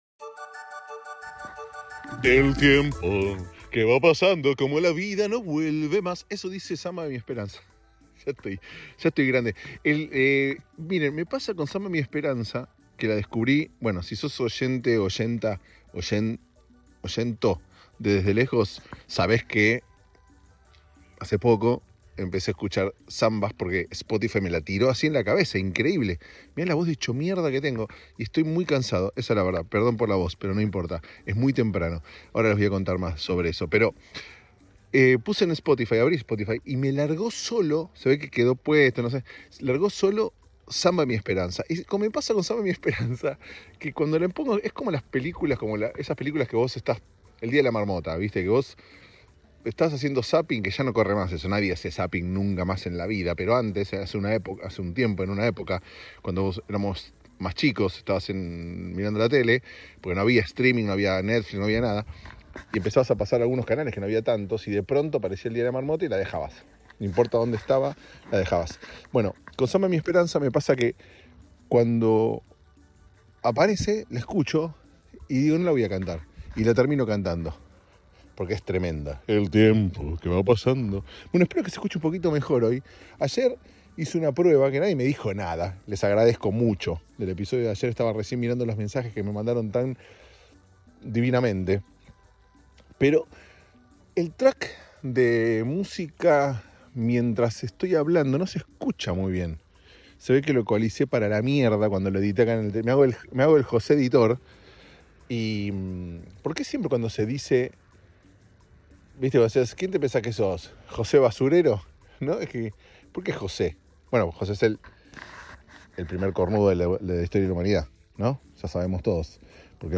Eso si: canté tipo carnaval carioca!